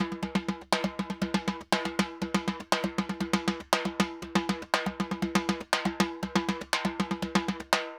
Timba_Baion 120_1.wav